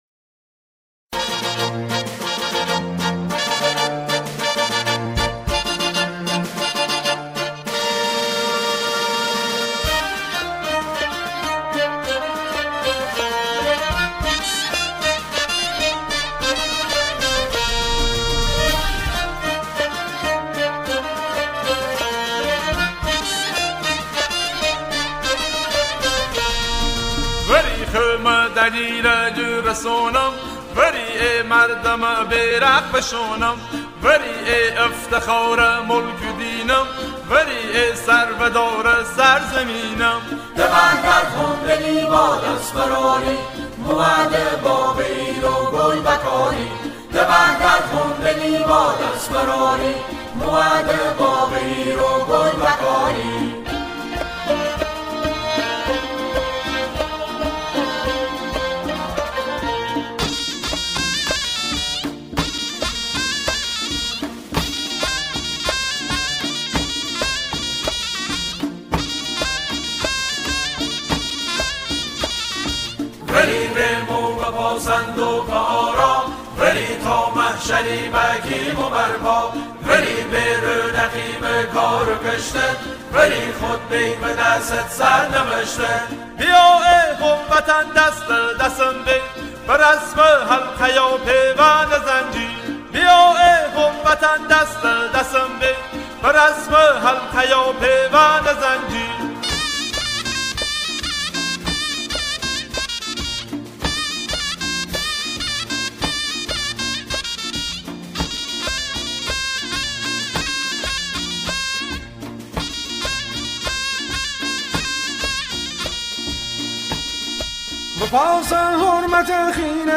به گویش لری
با همراهی گروهی از همخوانان